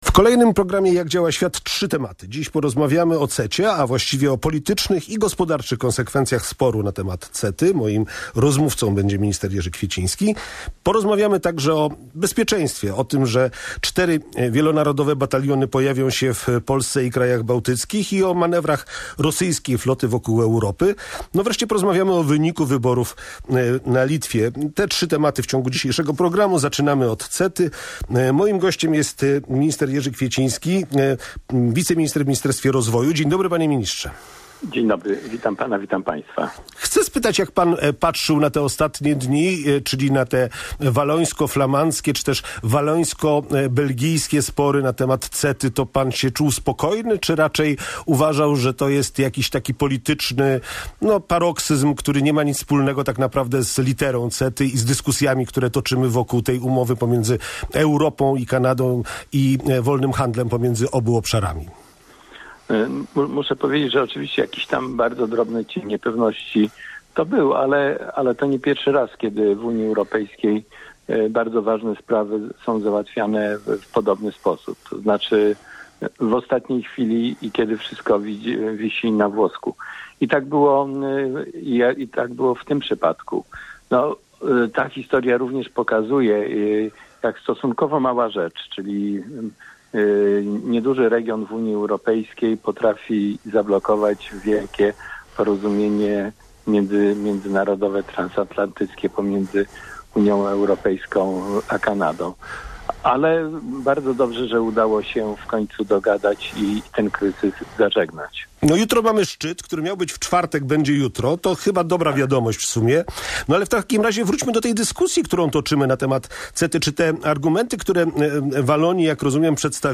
Jego gościem był dr Jerzy Kwieciński, wiceminister rozwoju.